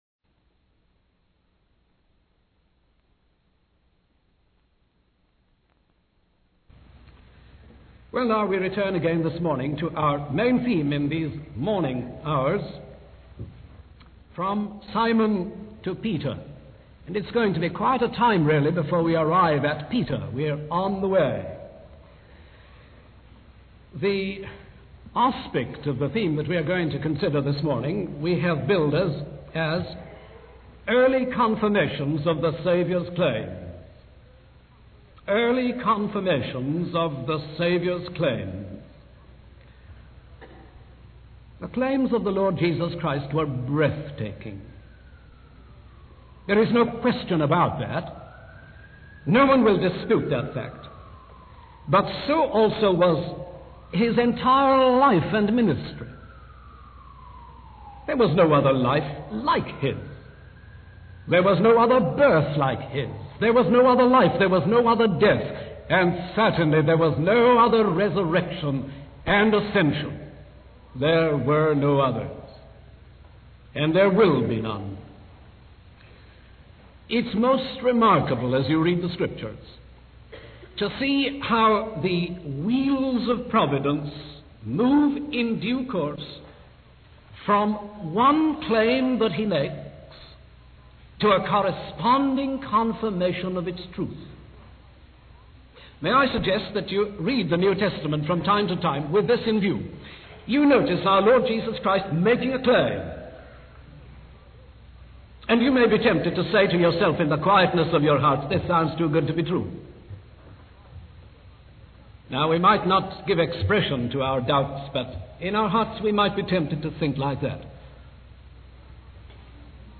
In this sermon, the speaker focuses on the first four chapters of the Gospel of John, which serve to prove that Jesus truly knew Simon Peter. The speaker emphasizes that Jesus not only knew Peter, but also had the power and grace to transform him. The sermon highlights three illustrations of Jesus' ability to know others, with the first being Nathaniel.